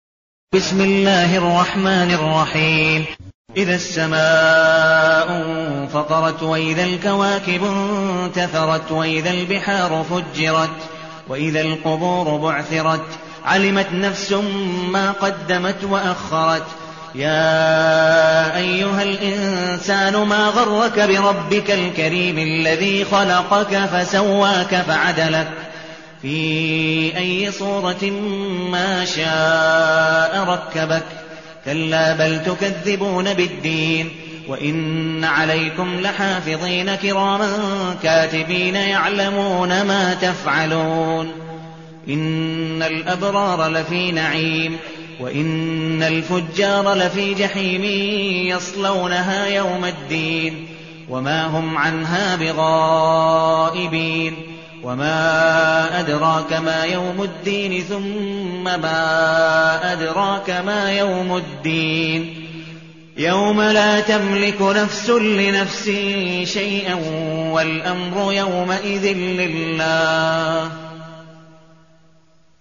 المكان: المسجد النبوي الشيخ: عبدالودود بن مقبول حنيف عبدالودود بن مقبول حنيف الانفطار